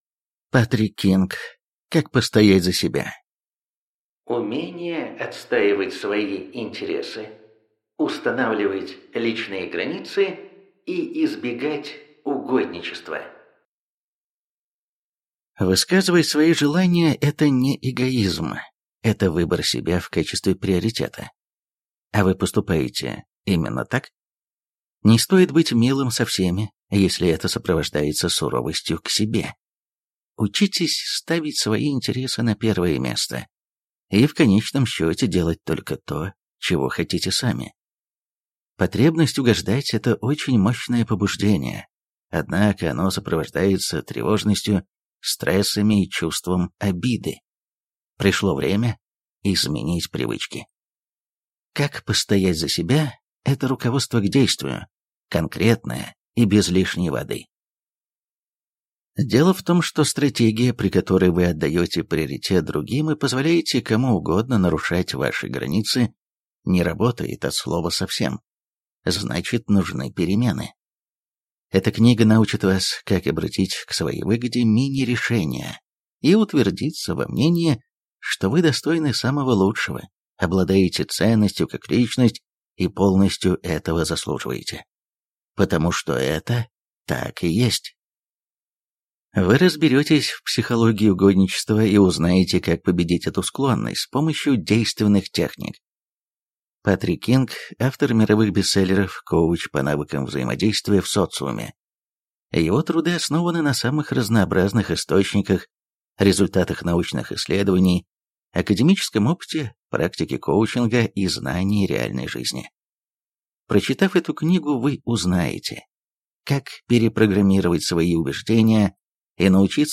Аудиокнига Как постоять за себя. Умение отстаивать свои интересы, устанавливать личные границы и избегать угодничества | Библиотека аудиокниг